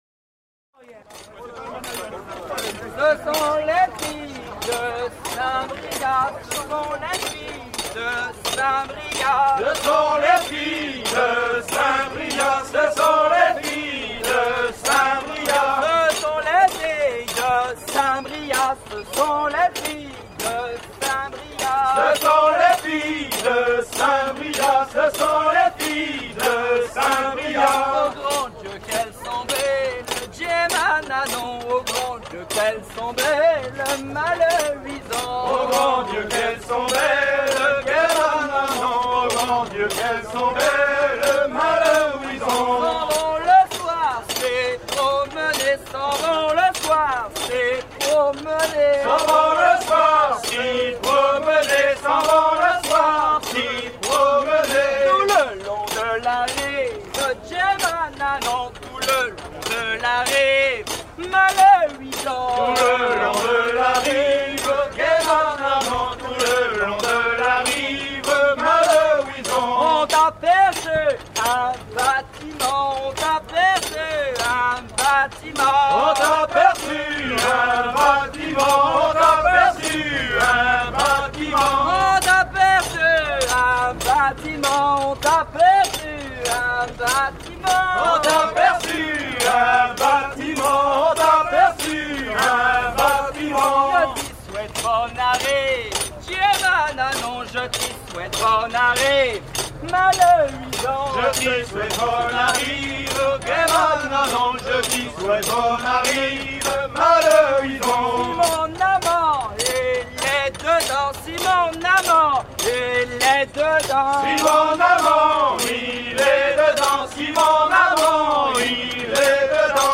à virer au cabestan
maritimes
Genre laisse
Pièce musicale éditée